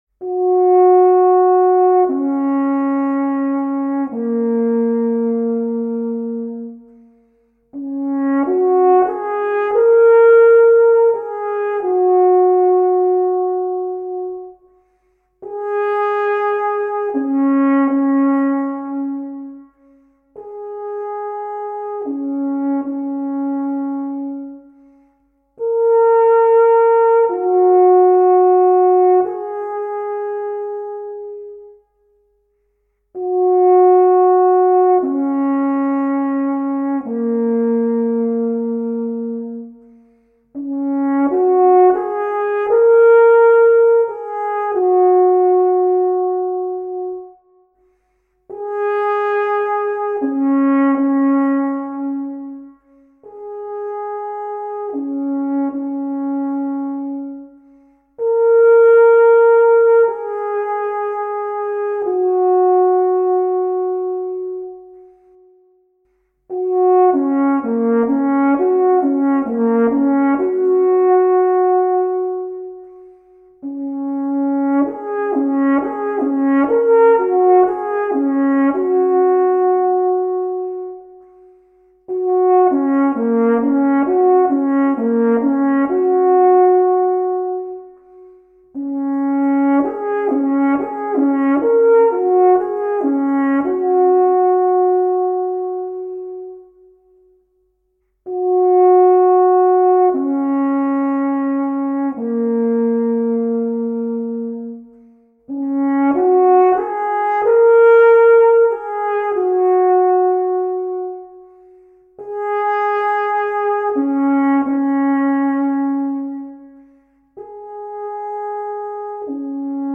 Alphornsolistin